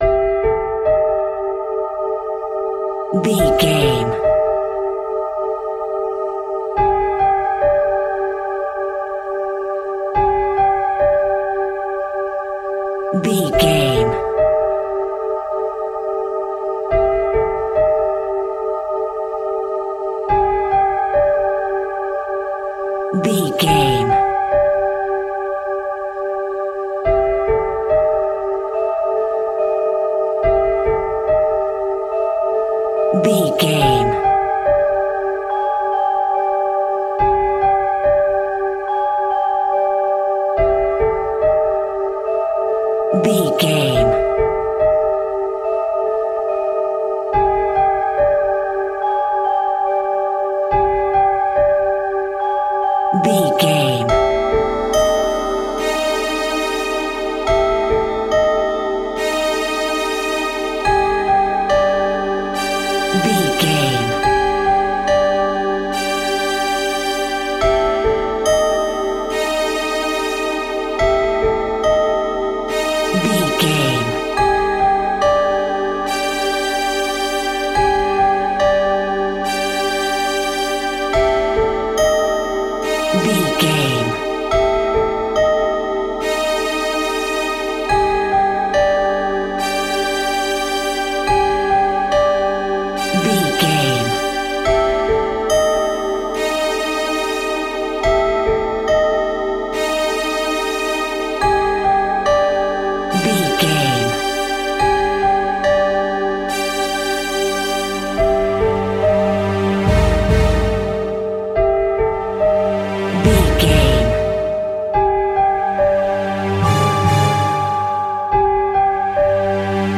Atonal
Slow
scary
ominous
dark
haunting
eerie
melancholic
piano
synthesiser
strings
horror music
Horror Pads